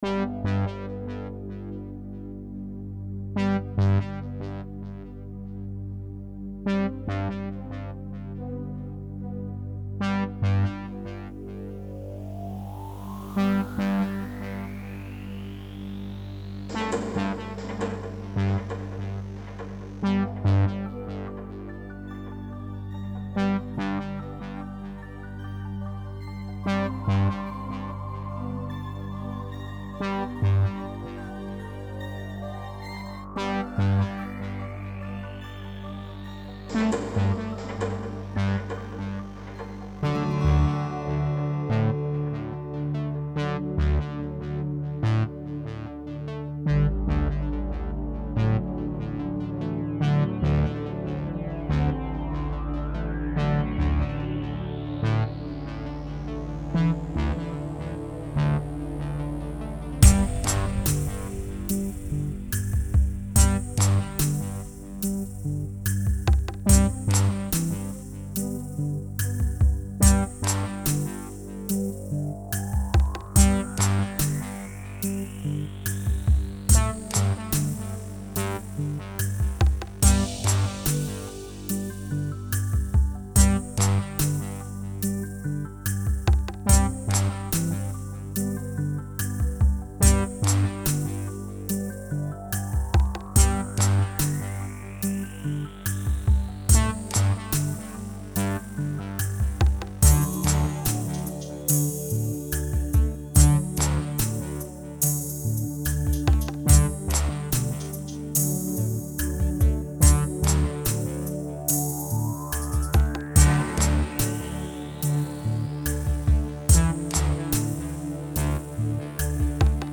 Synthétiseurs et cordes